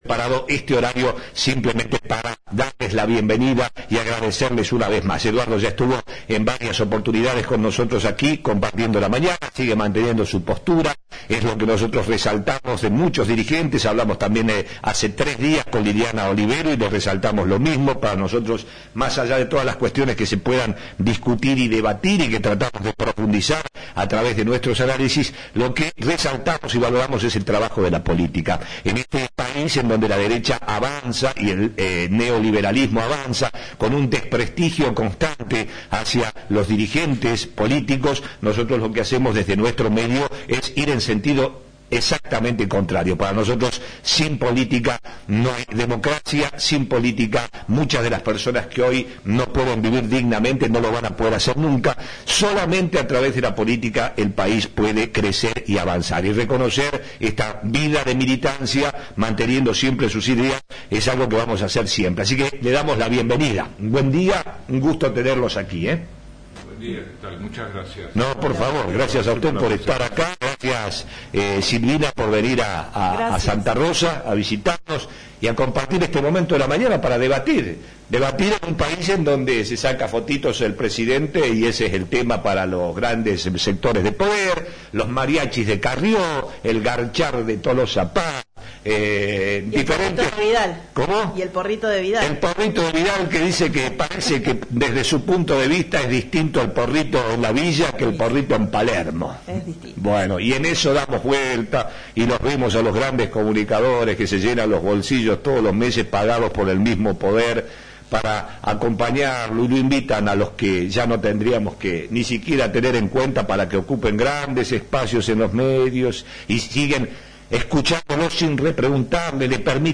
visitaron nuestros estudios. Cuestionaron fuertemente la forma de hacer política de los últimos gobiernos y resaltaron que la movilización de los trabajadores en Córdoba cada vez se nota mas y ésto marca un tiempo de cambio que se va acelerando para que la provincia tenga un giro en un futuro cercano.